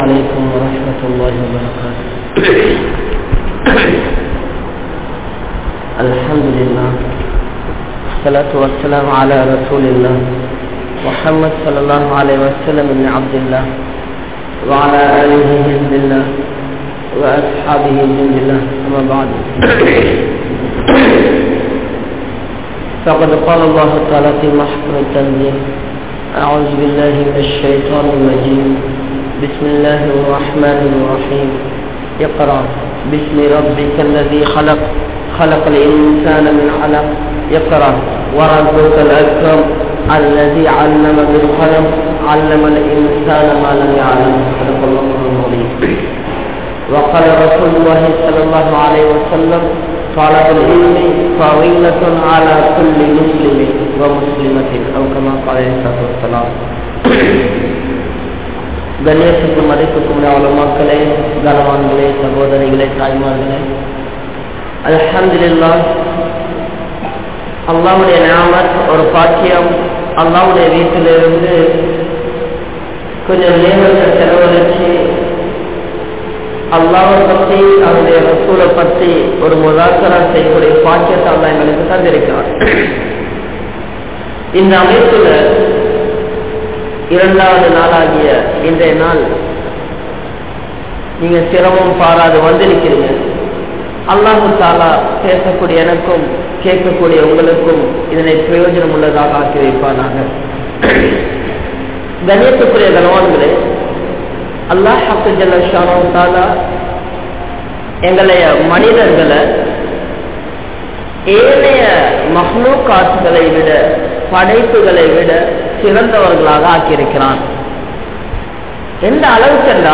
Islamum Indraya Kalvi Muraium | Audio Bayans | All Ceylon Muslim Youth Community | Addalaichenai